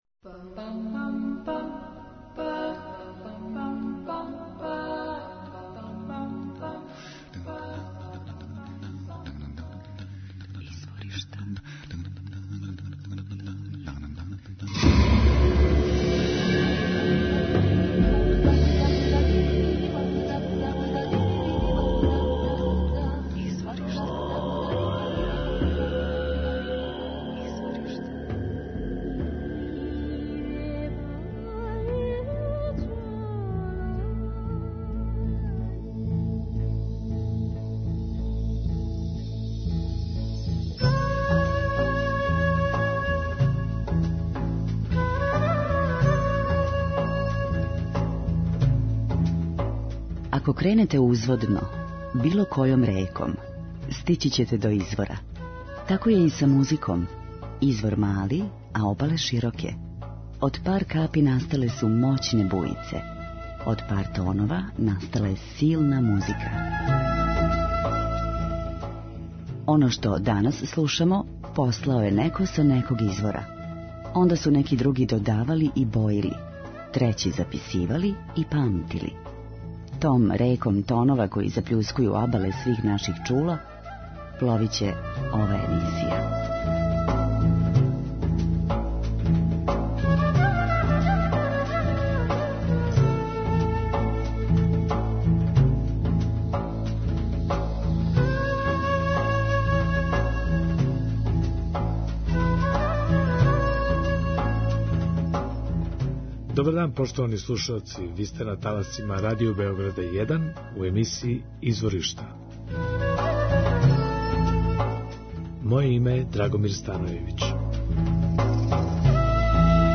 Мешавина индијске романтичне поезије 19. века
композитор и виолиниста